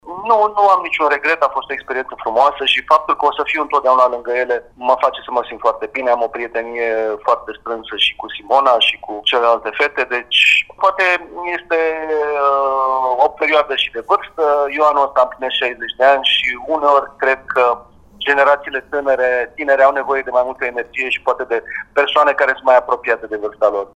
Într-un interviu pentru Radio Timișoara, Florin Segărceanu a anunțat că renunță la postul de căpitan nejucător: